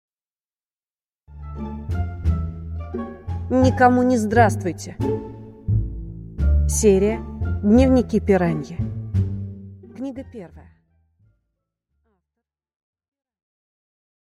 Аудиокнига Никому не здравствуйте | Библиотека аудиокниг